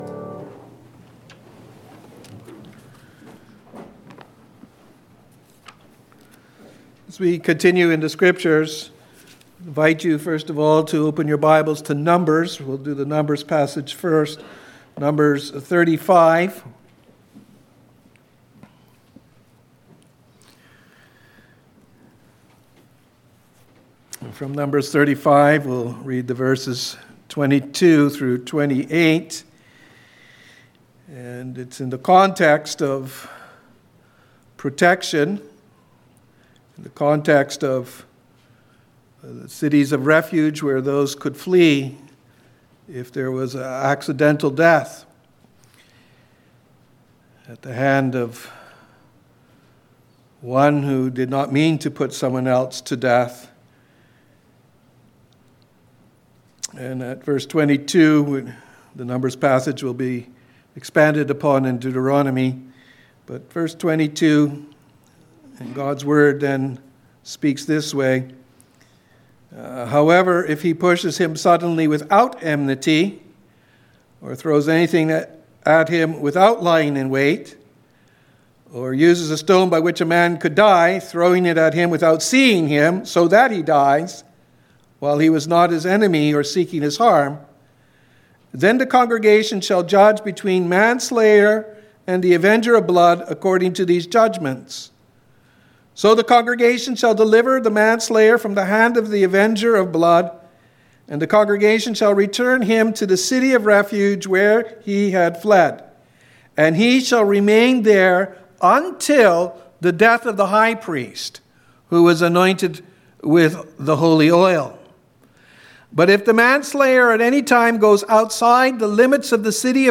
5.Sermon_-The-Road-to-the-City-of-Refuge.mp3